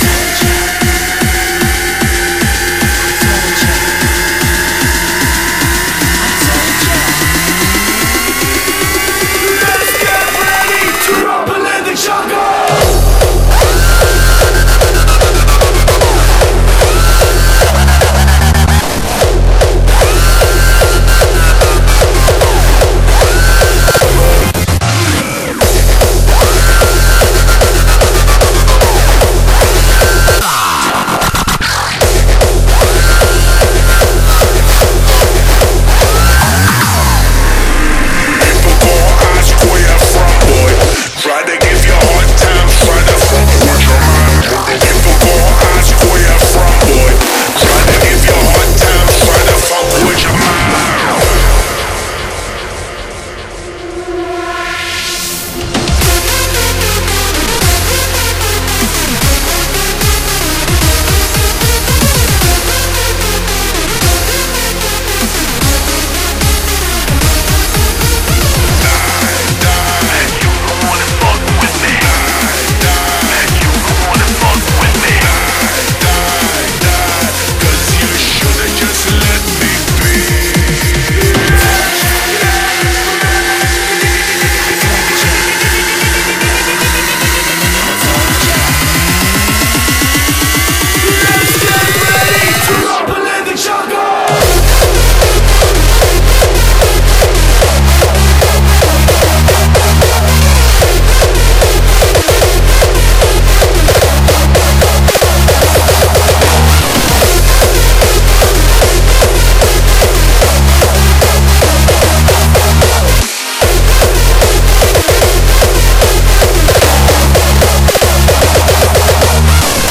BPM150
Audio QualityPerfect (High Quality)
Comments[HARDSTYLE]